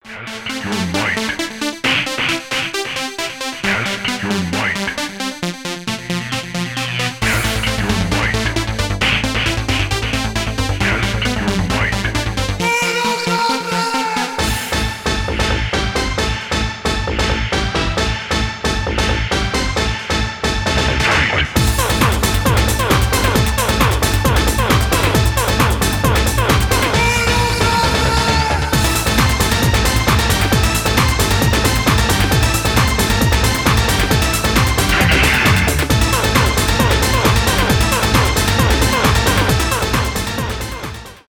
техно